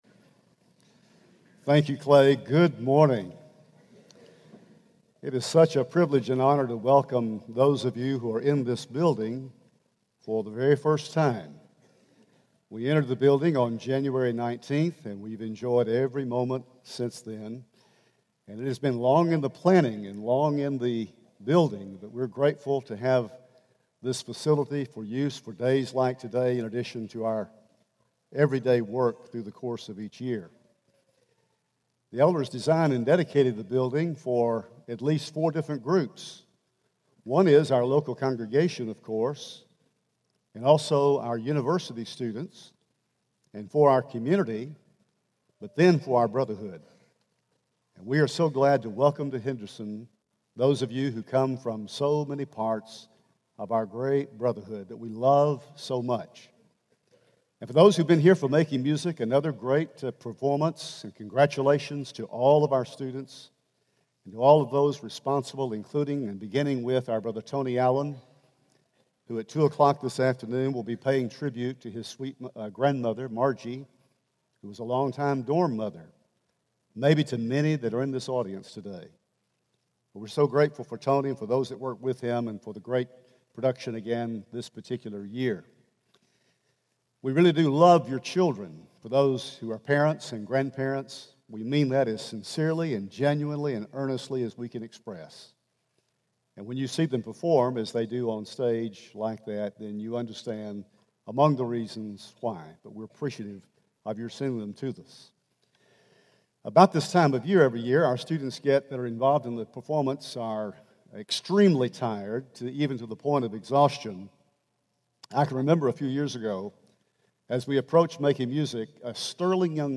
Worship